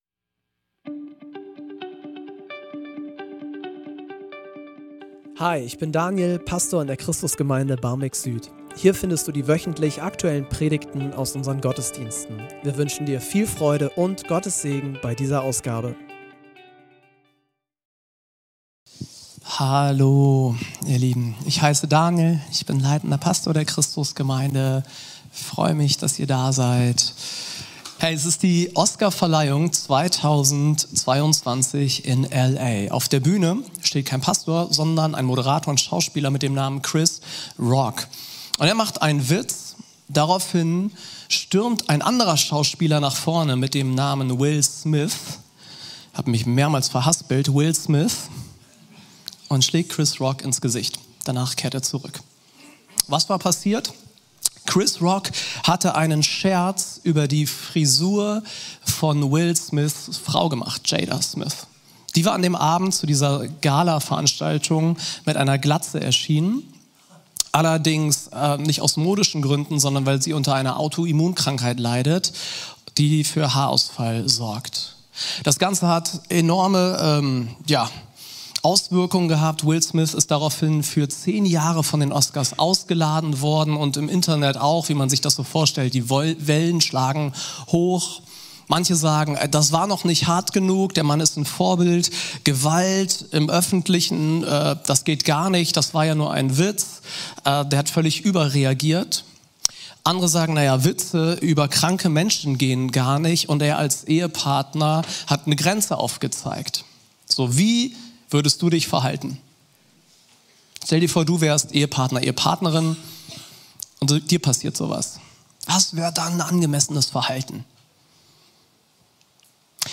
Religion , Christentum , Religion & Spiritualität